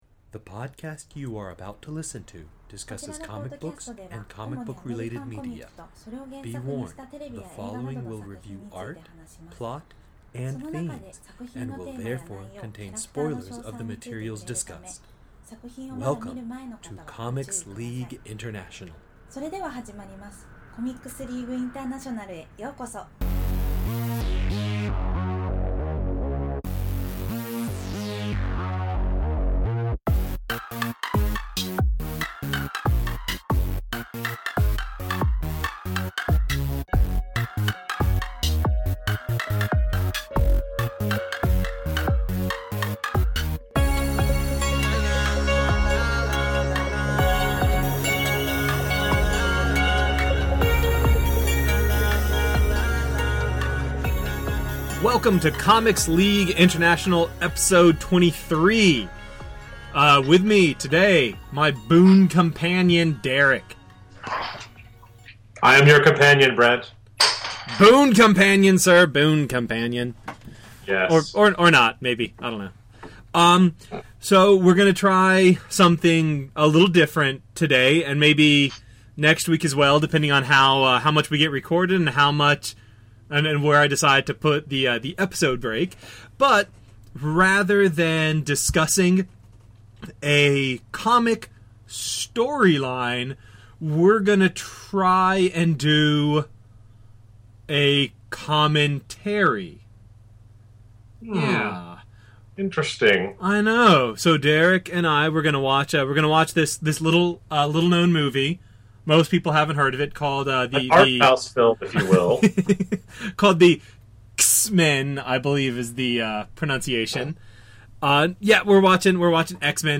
CLI 23: X-Men (the movie) Commentary, pt1
cli-23-x-men-the-movie-commentary.mp3